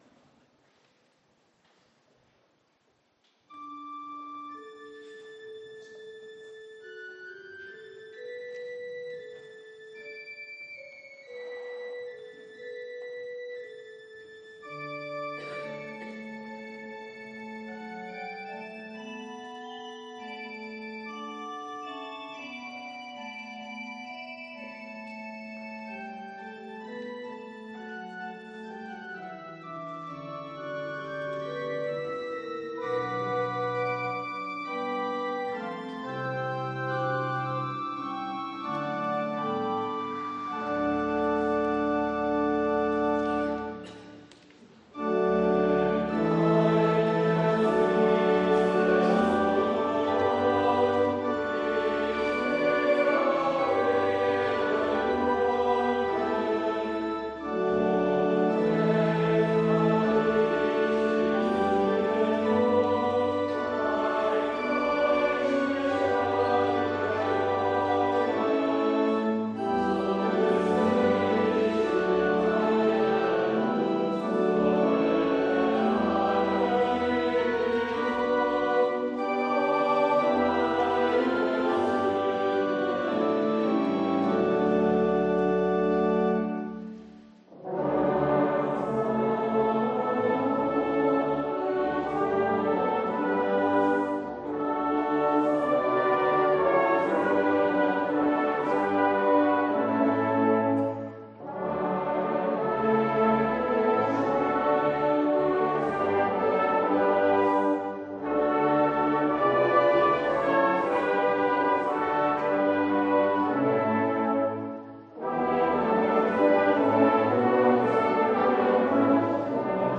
Audiomitschnitt unseres Gottesdienstes vom 1.Christtag 2022.